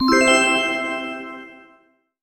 На этой странице собраны звуки электронной очереди — знакомые сигналы, голосовые объявления и фоновый шум.
Звуковое оповещение электронной очереди